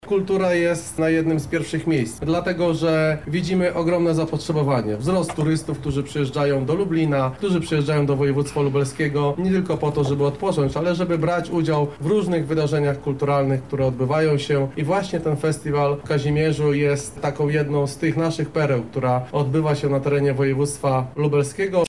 Marcin Szewczak – mówi Członek Zarządu Województwa Lubelskiego Marcin Szewczak.